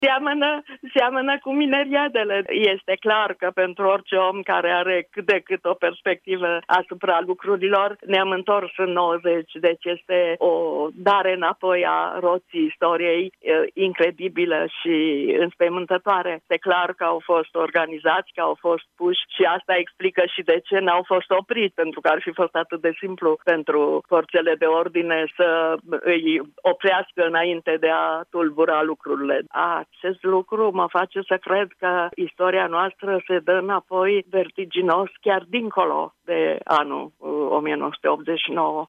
Într-un interviu pentru Europa FM, Ana Blandiana vorbește despre o întoarcere în timp a României.